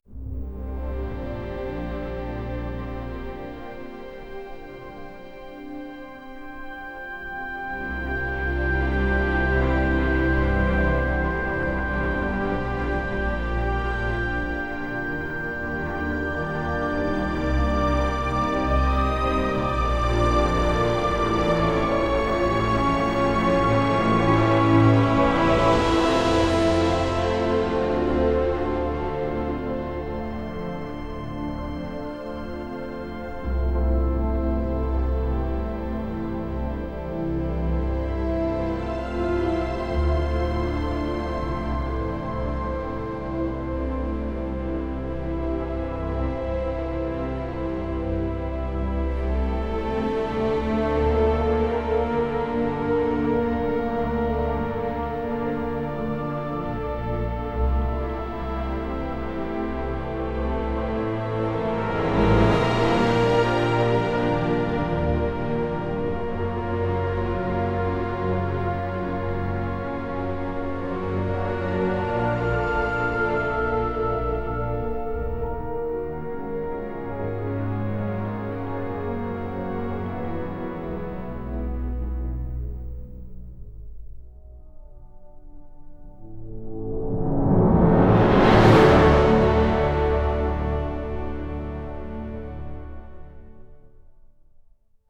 music
ChoirChordsBassMaster_5.wav